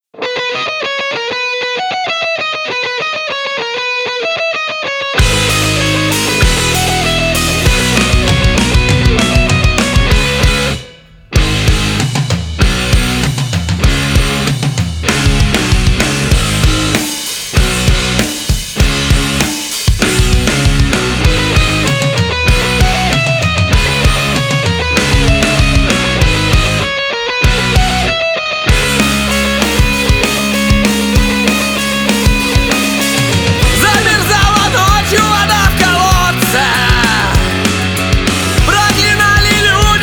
• Качество: 320, Stereo
электрогитара
динамичные
панк-рок
бас-гитара
punk rock